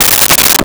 Toilet Paper Dispenser 03
Toilet Paper Dispenser 03.wav